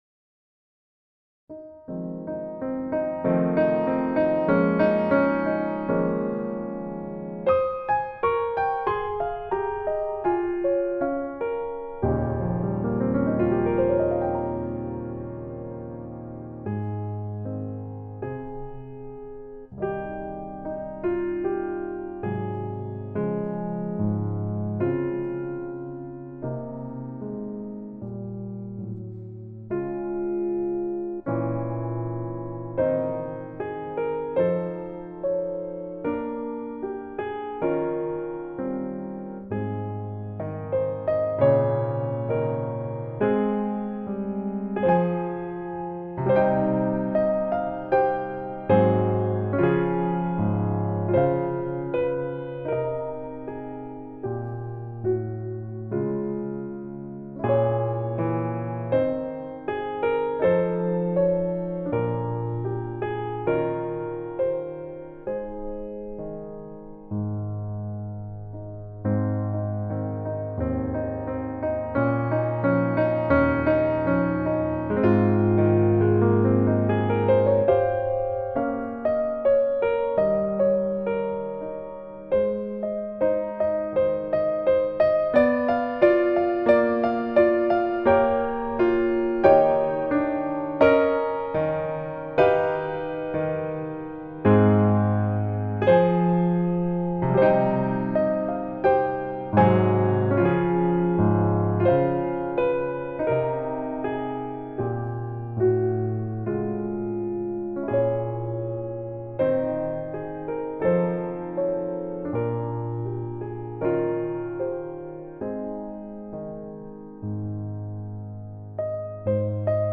Over The Rainbow (Gesang solo)
Hier findet ihr Text, Begleitstimmen in mittlerer und tiefer Lage, ein Demo und ein paar Tipps von mir, wie ihr euch diesen Song erarbeiten könnt.